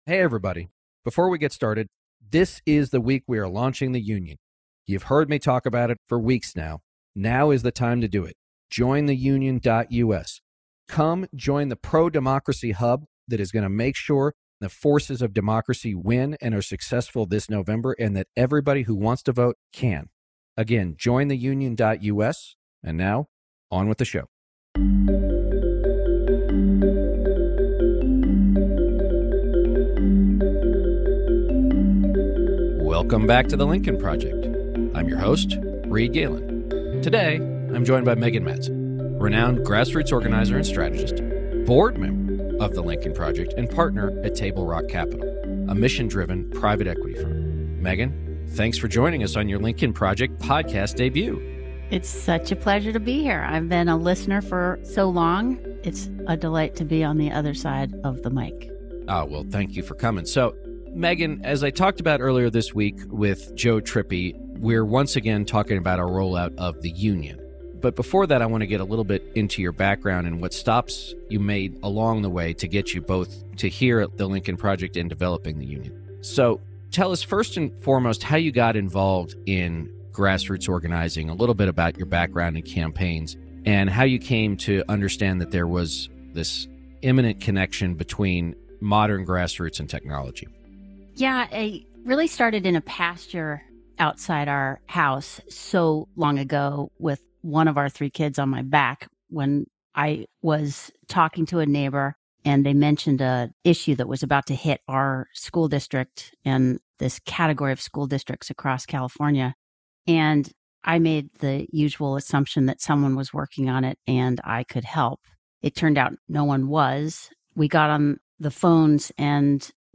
They continue the exciting conversation about the Lincoln Project’s brand new initiative, The Union, with special attention to its grassroots aspects and why it is so important for the pro-democracy coalition to get involved and build passion at the local level.